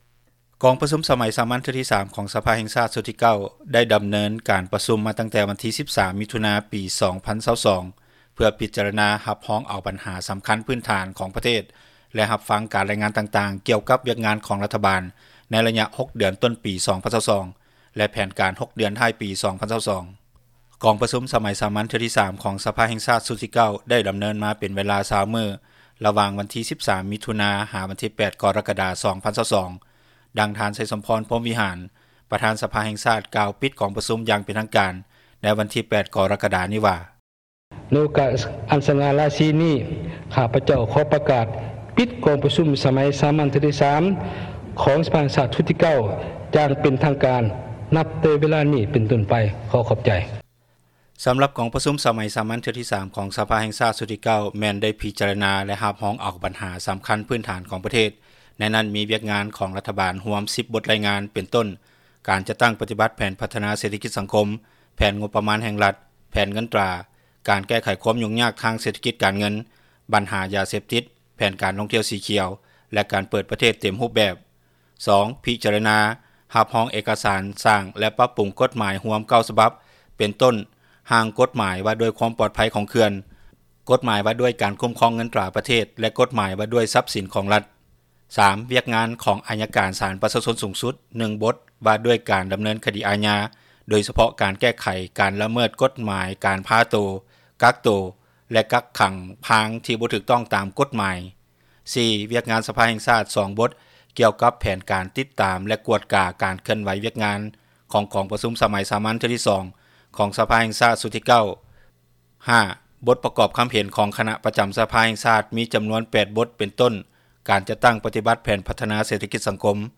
ດັ່ງປະຊາຊົນລາວ ໃນນະຄອນຫຼວງວຽງຈັນກ່າວຕໍ່ ວິທຍຸເອເຊັຽເສຣີໃນວັນທີ 8 ກໍຣະກະດານີ້ວ່າ:
ດັ່ງນັກວິຊາການດ້ານເສຖສາຕ-ສັງຄົມປະຈຳຢູ່ມຫາວິທຍາລັຍແຫ່ງຊາຕ ທ່ານນຶ່ງກ່າວຕໍ່ວິທຍຸເອເຊັຽເສຣີໃນວັນທີ 8 ກໍຣະກະດານີ້ວ່າ: